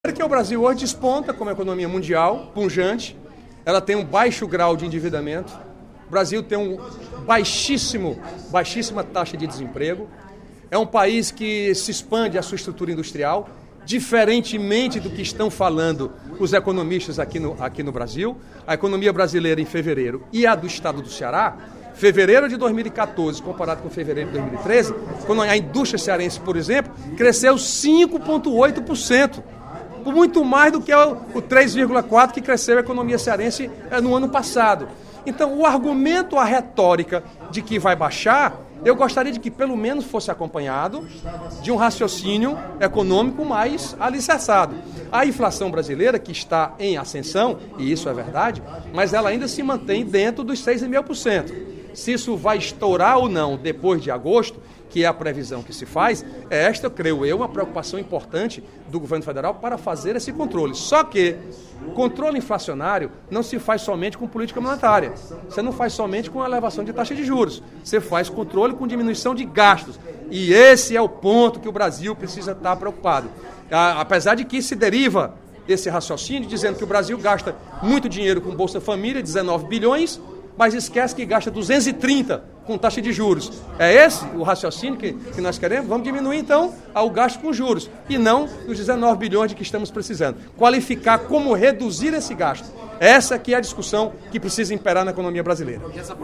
O deputado Mauro Filho (Pros) ocupou a tribuna, durante o primeiro expediente da sessão plenária desta quarta-feira (09/04), para rebater a estimativa divulgada pelo Fundo Monetário Internacional (FMI) que reduz, pela terceira vez consecutiva, a previsão de crescimento do Brasil em 2014. O parlamentar negou essa previsão e afirmou que é preciso, antes de tudo, saber quem está sugerindo e quais os interesses envolvidos.